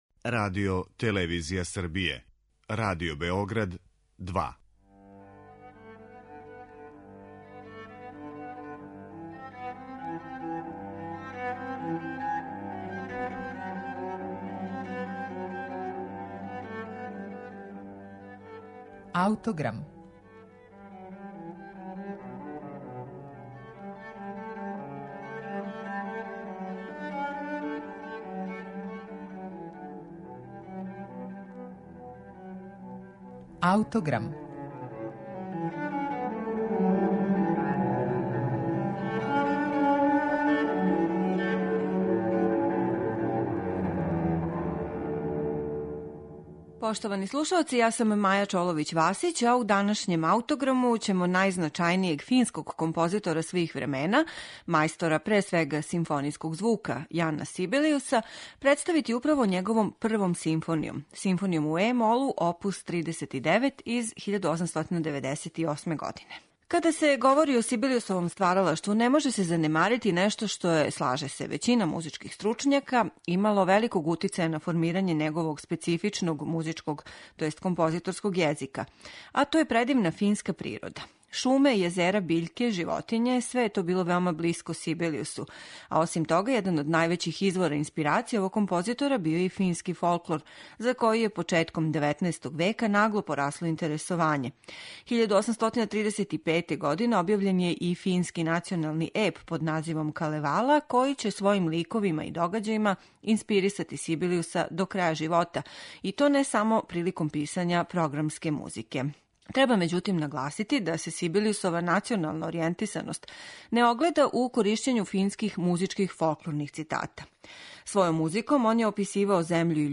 једноставачно дело